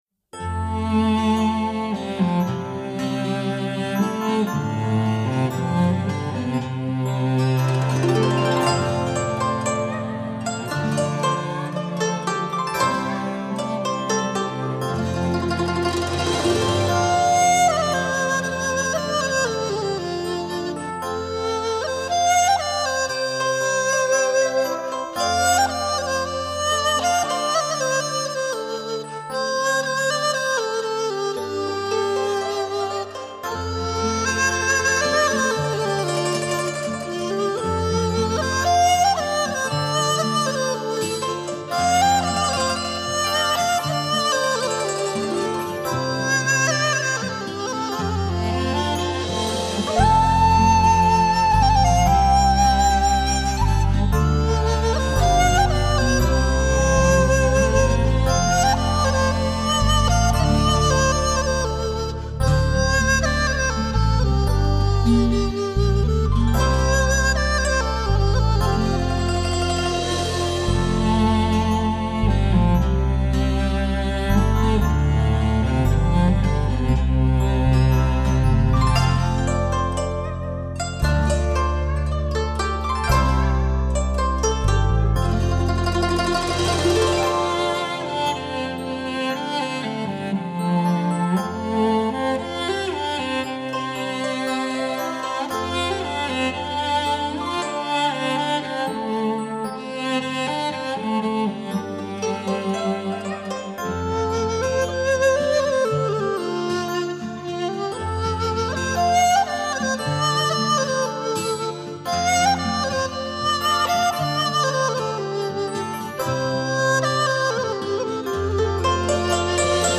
音乐情景和旋律走向的需要，增加了二胡、古筝、琵琶、大提琴，小提琴等各种不同